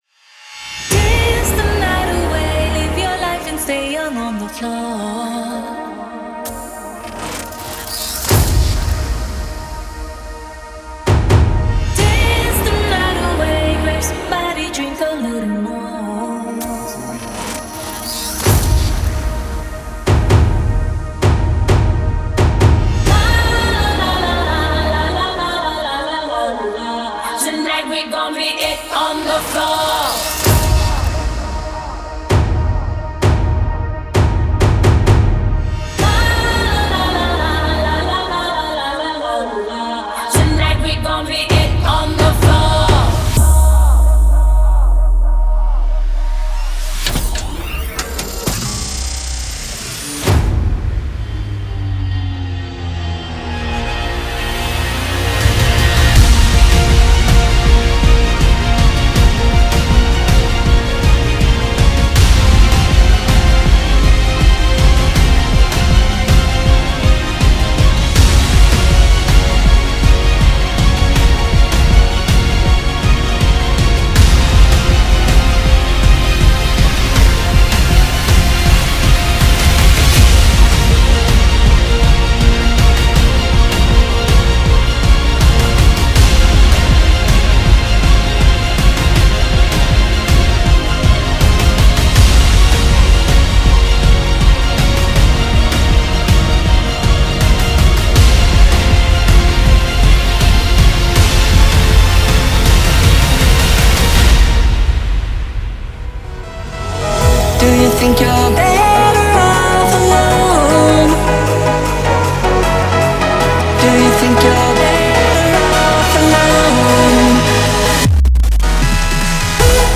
试听文件为低音质，下载后为无水印高音质文件 M币 20 超级会员 M币 10 购买下载 您当前未登录！